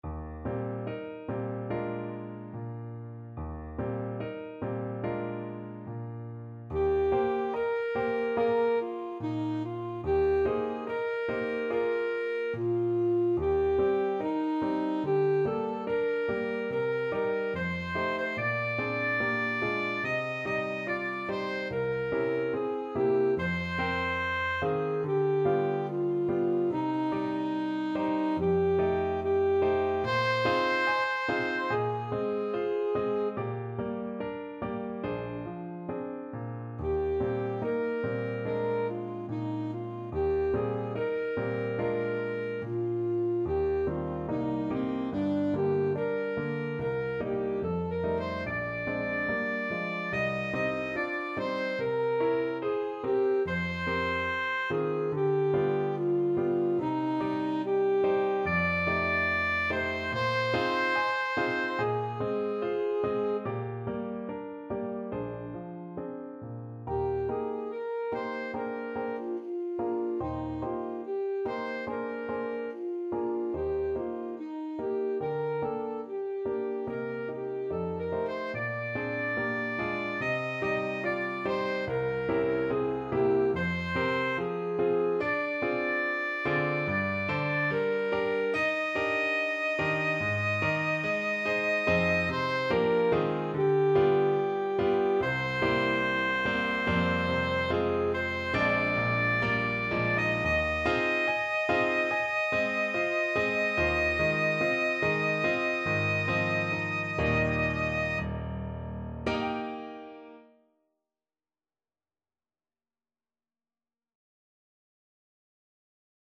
Alto Saxophone version
~ = 72 In moderate time
4/4 (View more 4/4 Music)
Classical (View more Classical Saxophone Music)